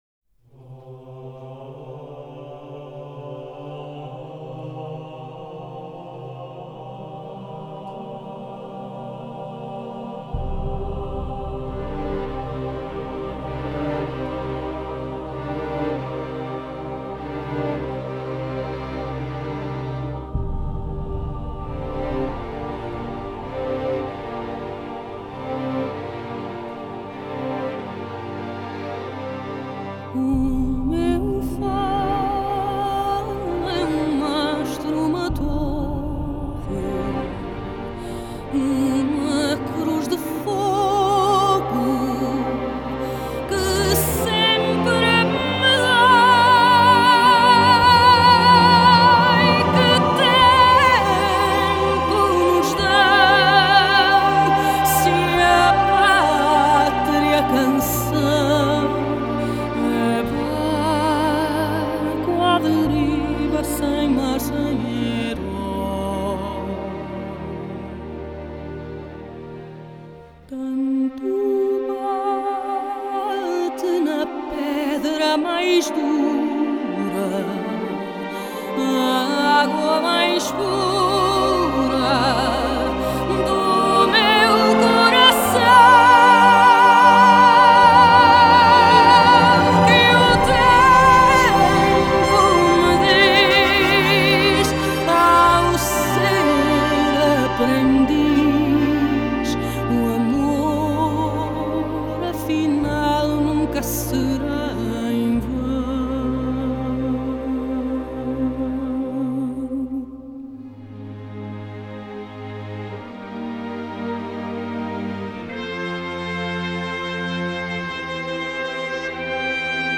Genre: Score
DSD-layer: Stereo, 5.1 Multichannel.